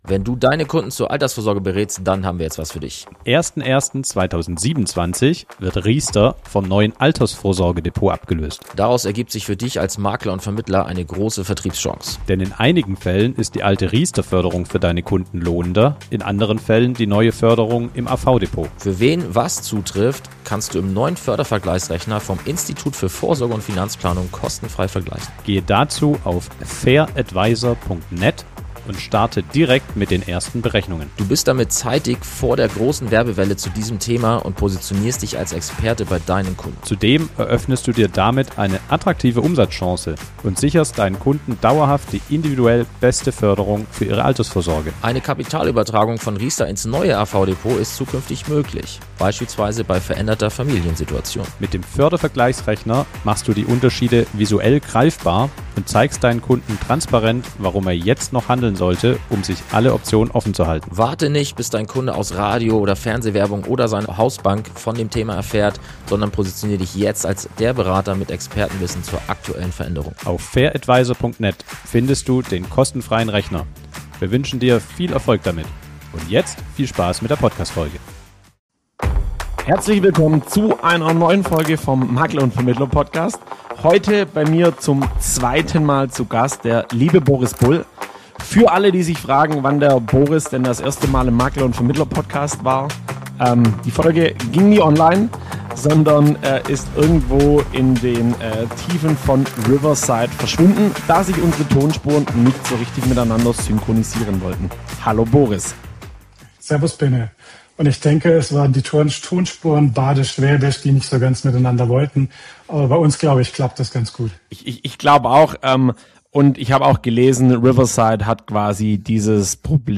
Nach einem humorvollen Einstieg mit „5 schnellen Fragen“ dreht sich das Gespräch um weit mehr als nur Versicherungsberatung für Ärzte.